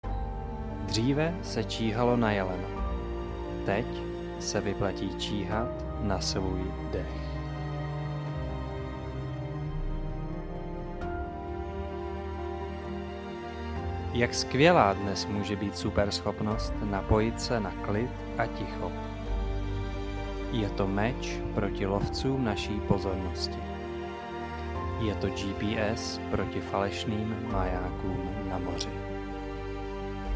Svůj klidný hlas mohu nahrát na profesionální mikrofon a poslat Vám text, který si zvolíte, v audioformátu, který si vyberete.
Klidný mužský hlas